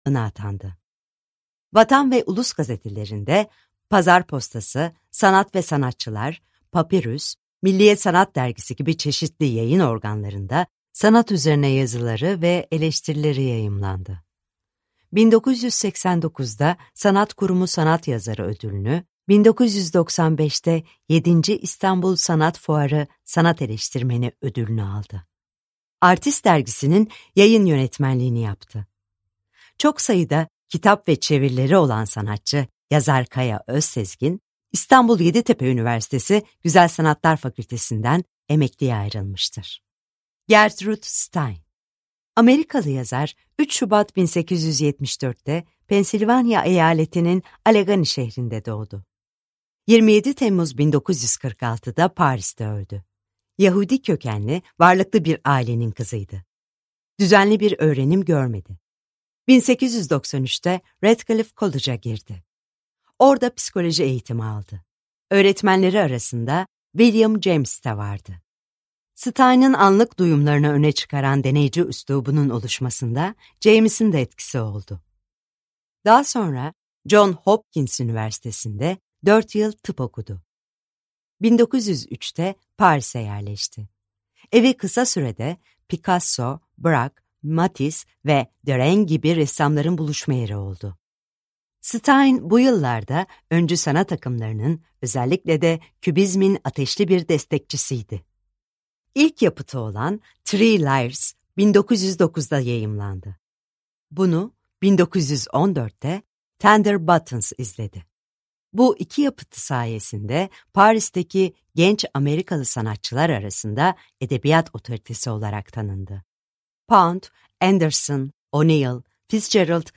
Picasso - Seslenen Kitap